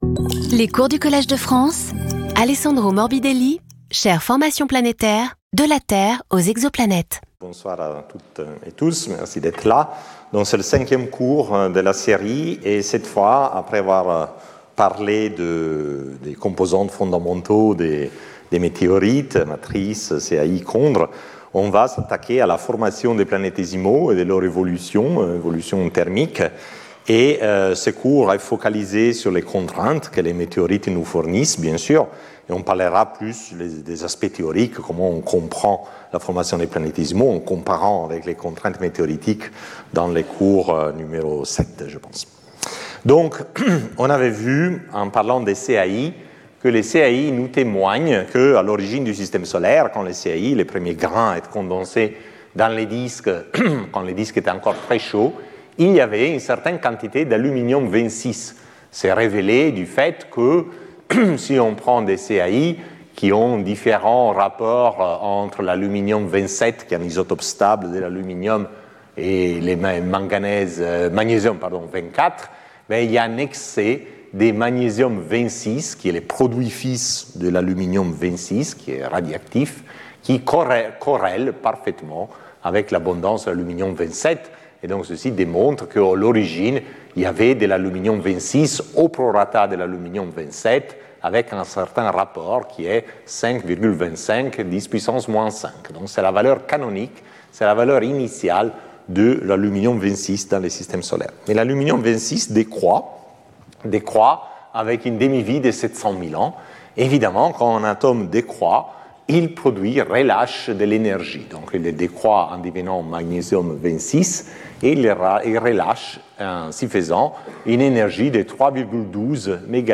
Lecture audio
Alessandro Morbidelli Professeur du Collège de France